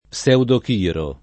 pseudochiro [ p SH udok & ro ] s. m. (zool.)